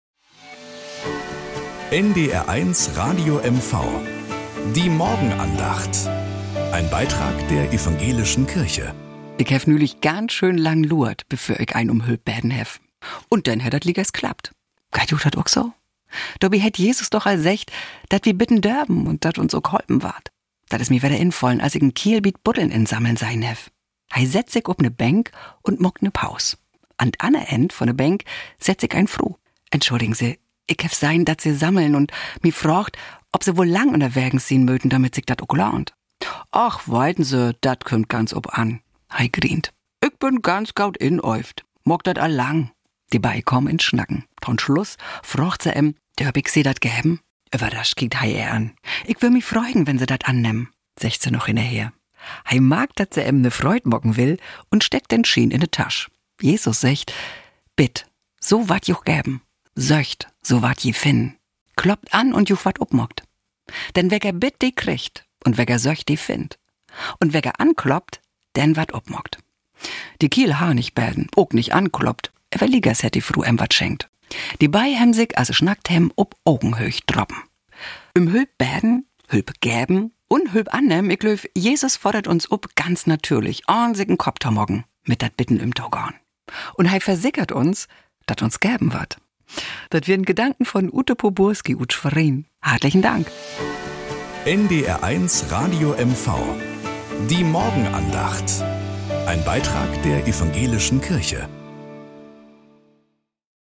Morgenandacht auf NDR 1 Radio MV - 29.04.2025